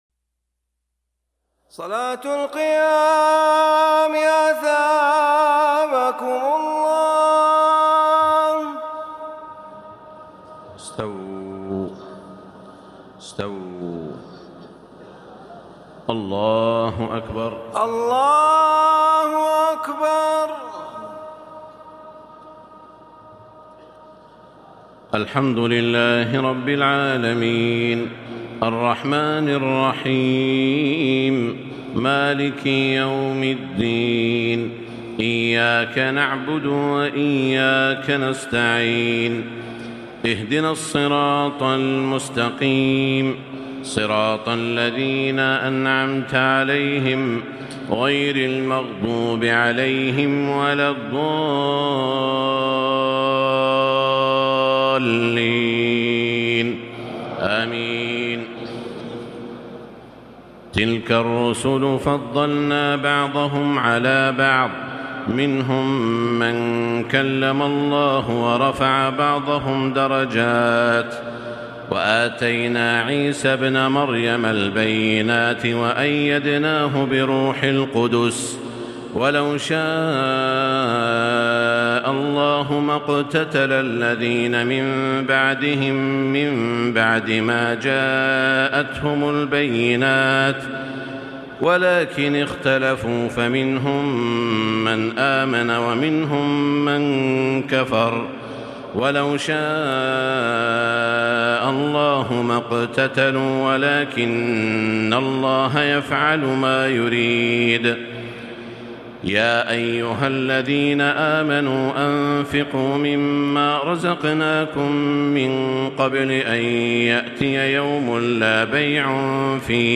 تهجد ليلة 23 رمضان 1440هـ من سورتي البقرة (253-286) و آل عمران (1-32) Tahajjud 23 st night Ramadan 1440H from Surah Al-Baqara and Aal-i-Imraan > تراويح الحرم المكي عام 1440 🕋 > التراويح - تلاوات الحرمين